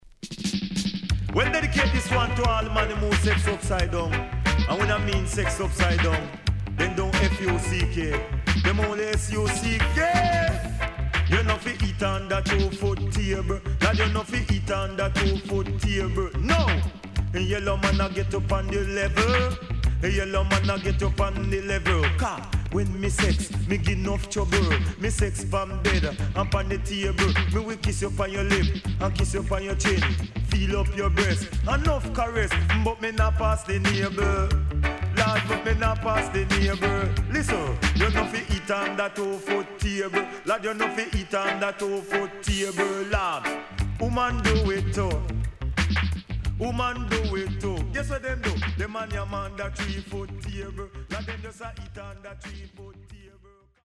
HOME > LP [DANCEHALL]